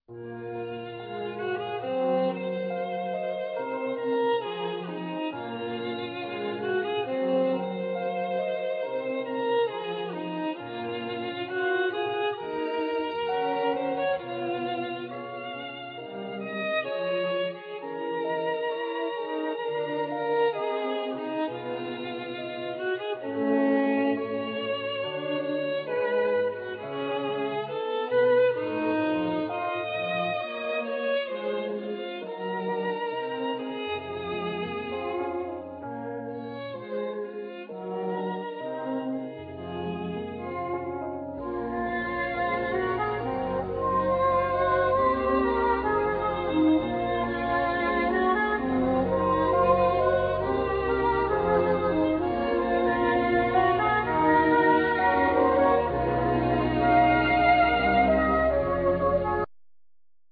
Alto+Soprano sax,Synthesizer
Keyboards
Piano
Tenor+Soprano sax.Violin
Guitar
Bass,Byan
Drums
Percussions